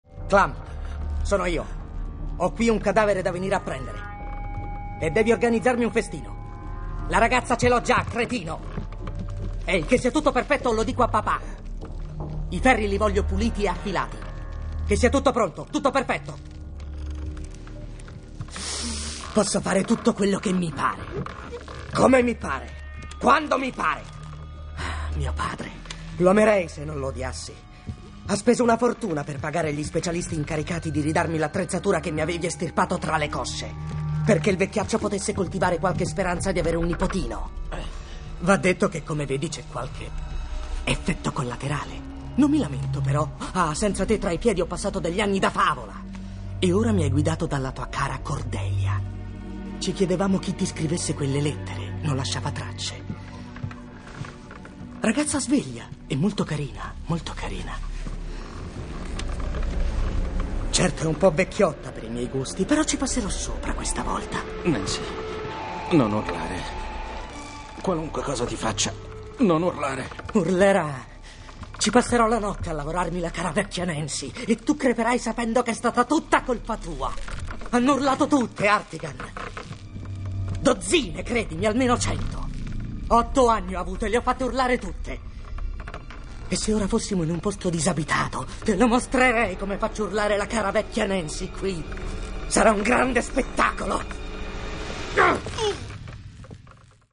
voce di Massimiliano Alto nel film "Sin City", in cui doppia Nick Stahl.